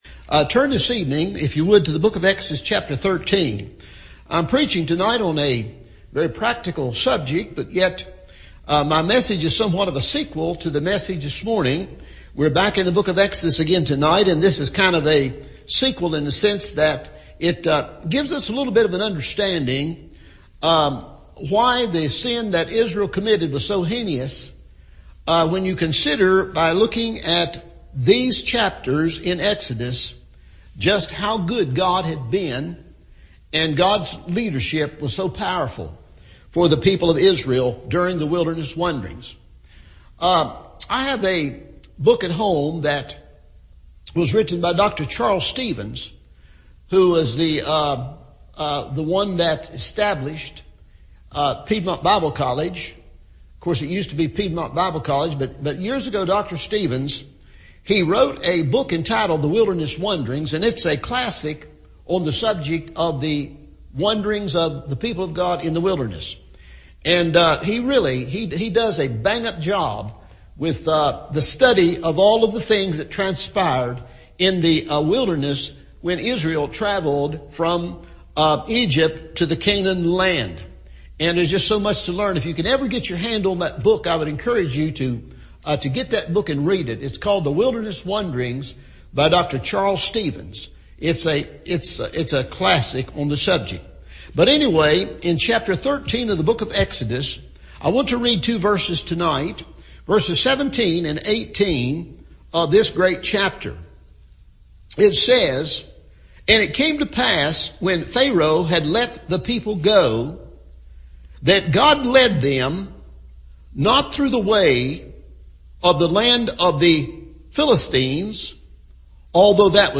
It’s A Mighty Rough Road – Evening Service